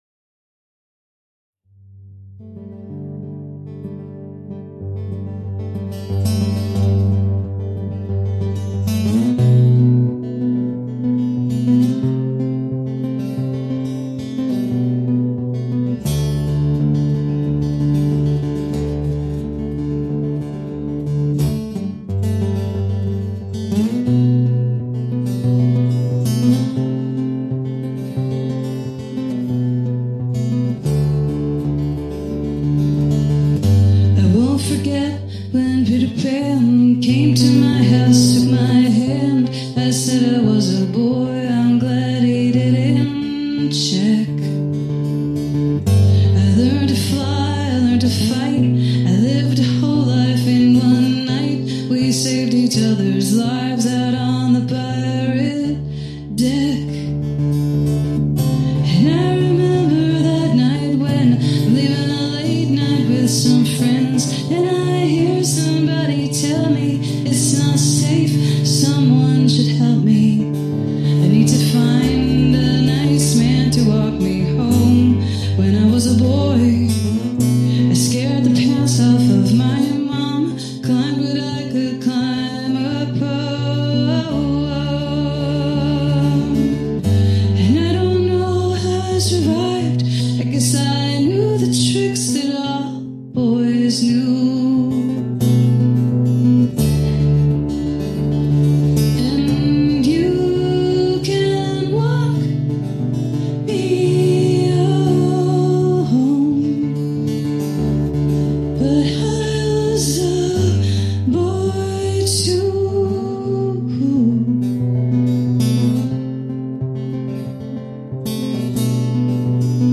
Live recording
seasoned singer-songwriter, performing artist, and author
as part of a solo concert in the Hawthorne Barn